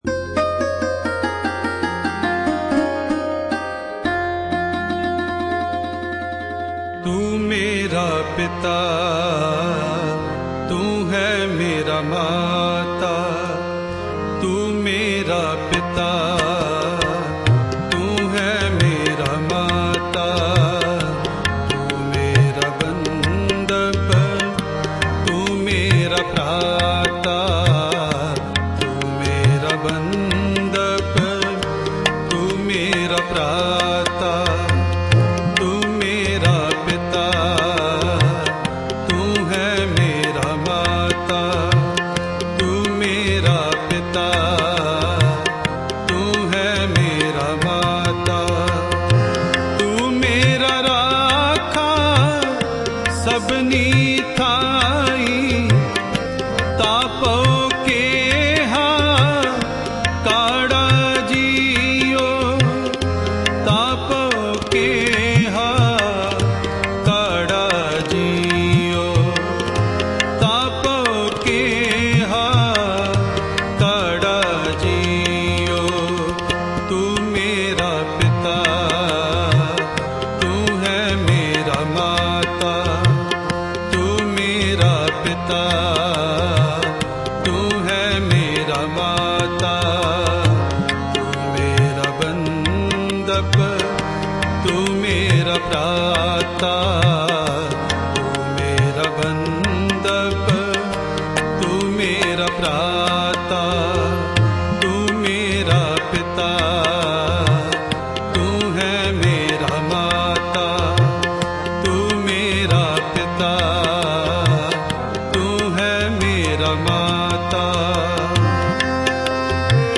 Gurbani Shabad Kirtan